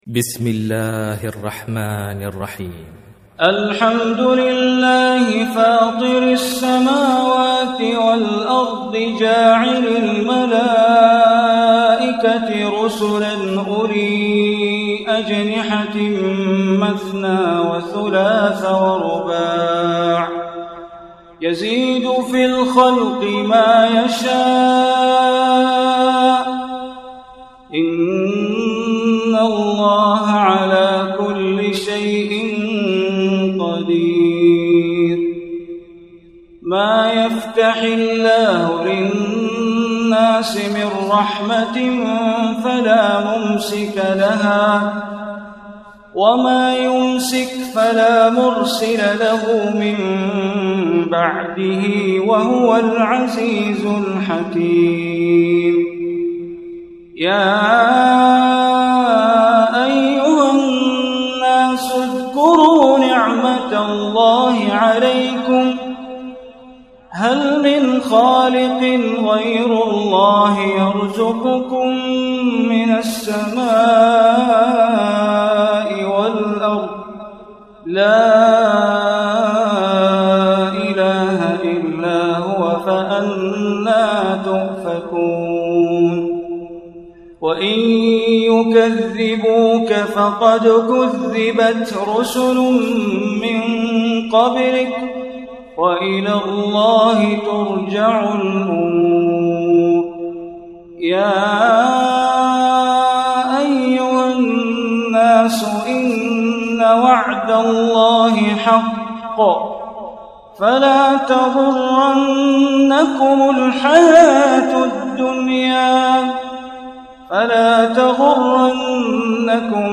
Surah Fatir Recitation by Sheikh Bandar Baleela